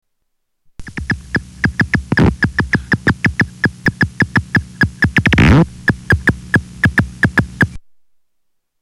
Silver hair bat echolocation
Category: Animals/Nature   Right: Personal